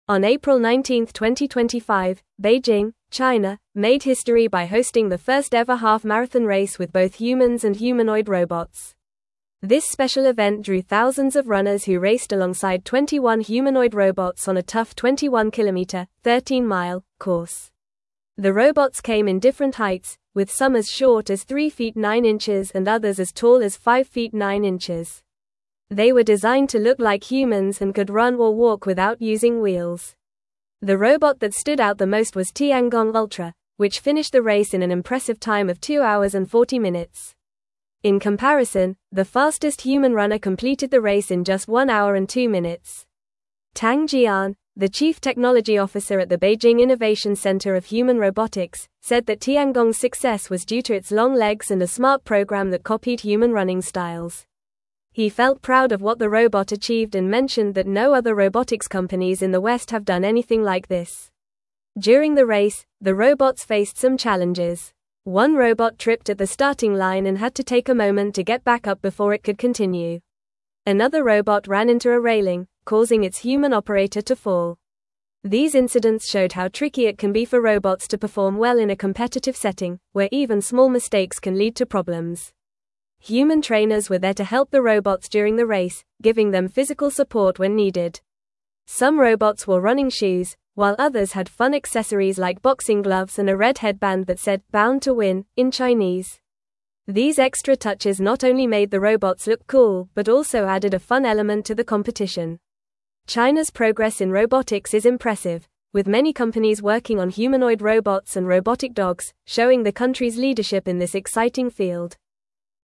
Fast
English-Newsroom-Upper-Intermediate-FAST-Reading-China-Hosts-Historic-Half-Marathon-with-Humans-and-Robots.mp3